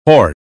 us_phonetics_sound_ho_rse_2023feb.mp3